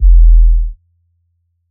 DB - Kick (18).wav